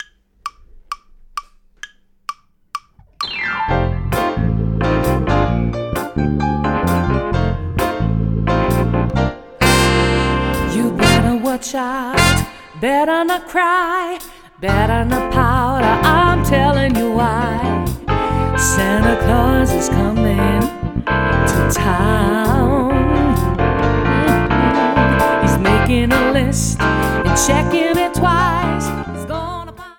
Besetzung: Schlagzeug
Drumset Playalongs